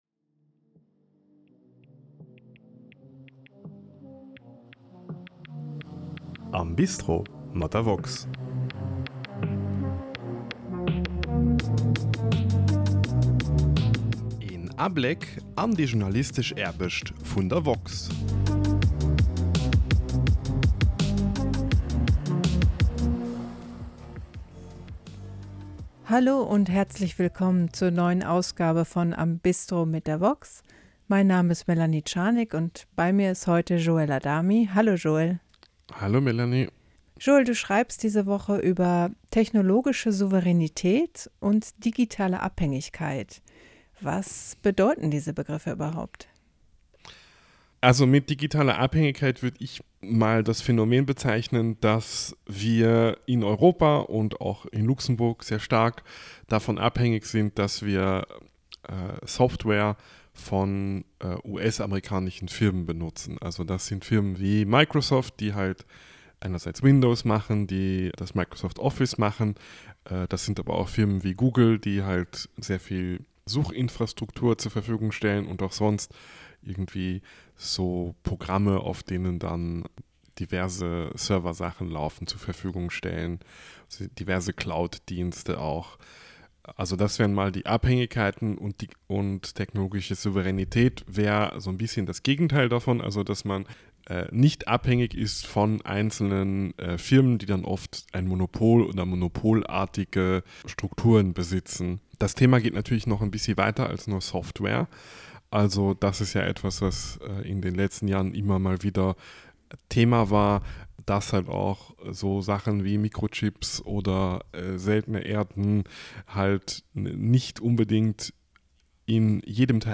Am Gespréich